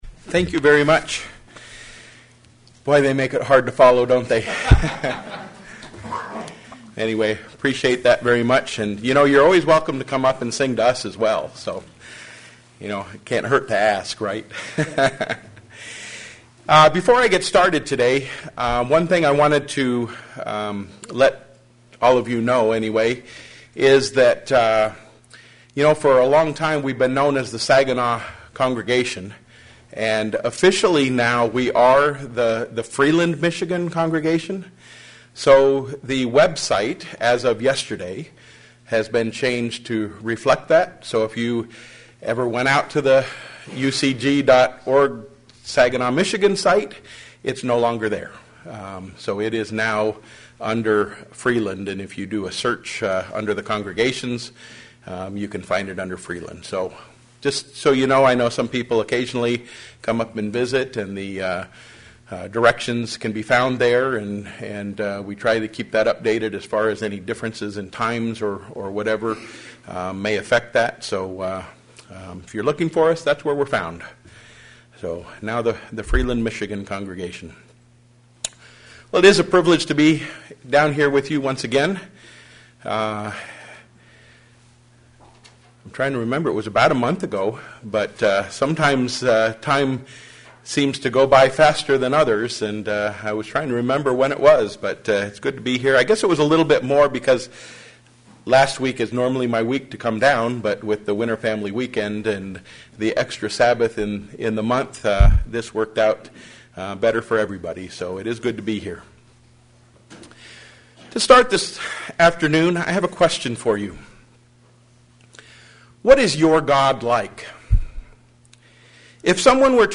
Given in Flint, MI
Is God sovereign over every area of your life? sermon Studying the bible?